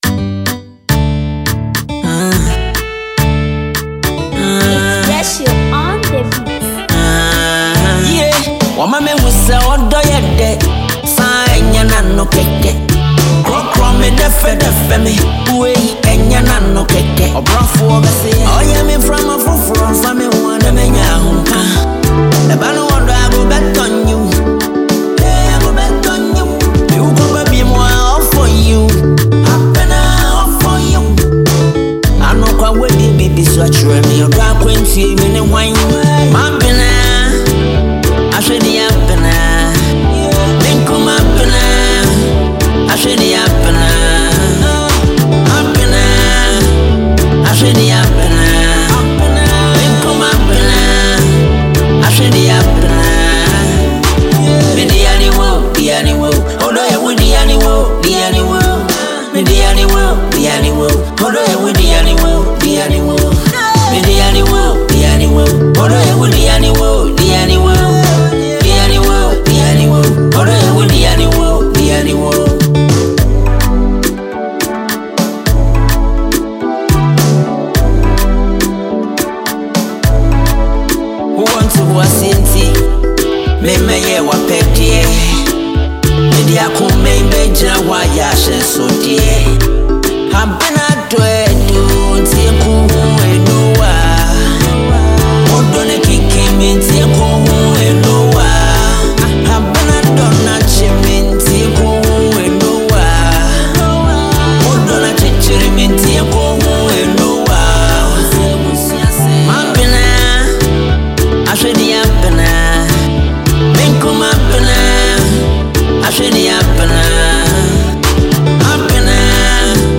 a Ghanaian highlife artist